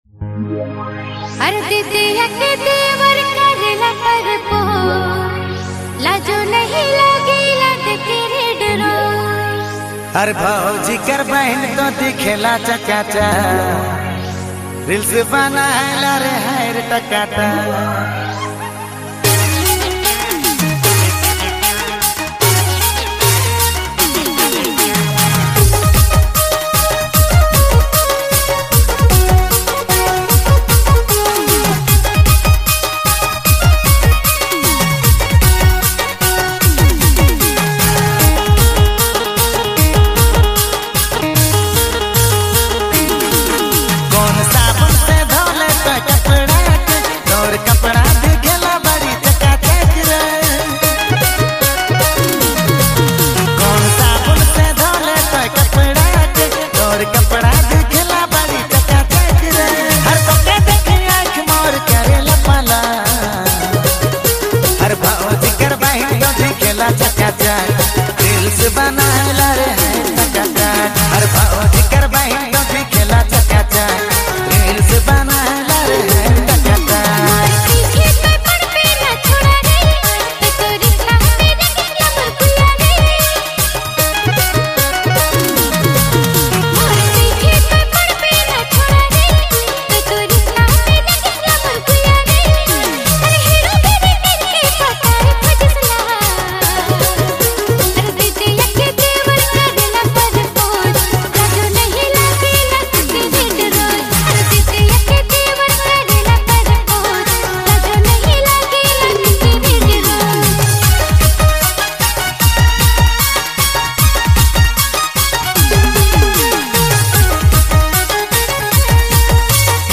Nagpuri